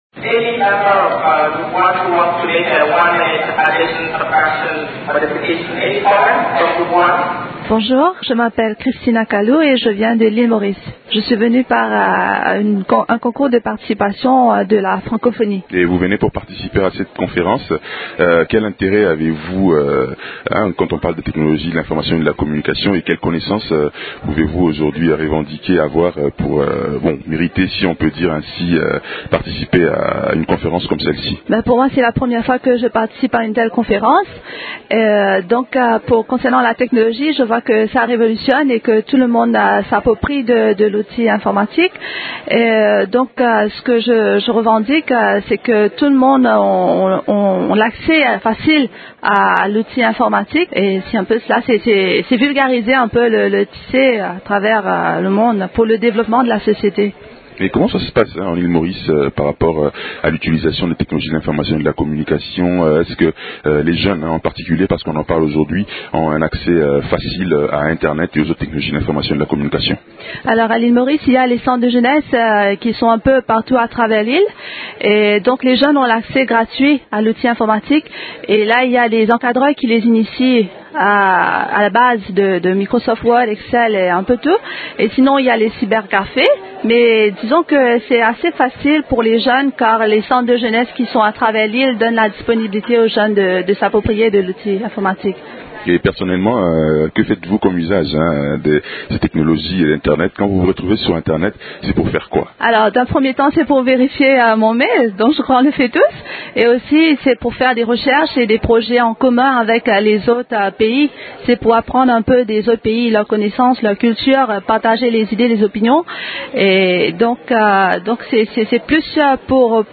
Intervew de quelques participants